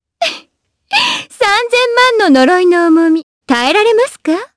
Shamilla-Vox_Skill3_jp.wav